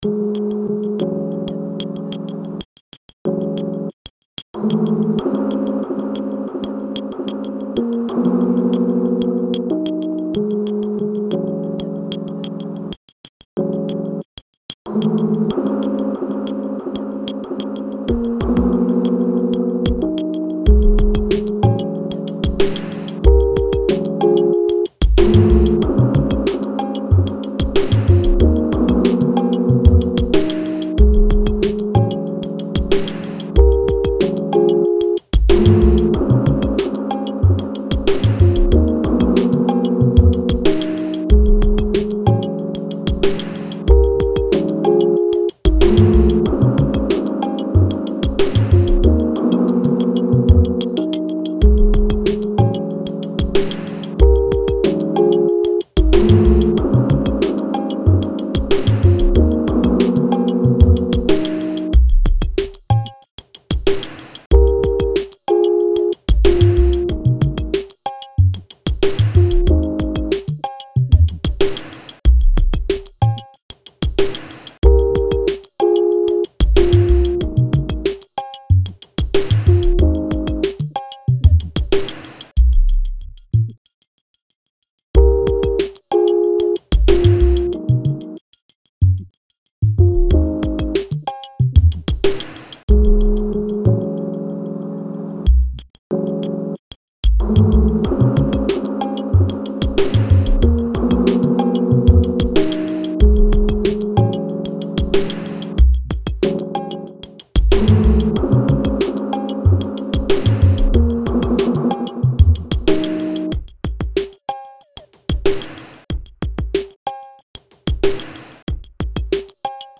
a handful of cool slowed motion beats